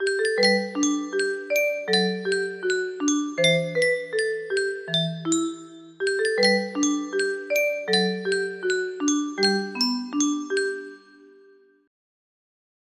me music box melody
Full range 60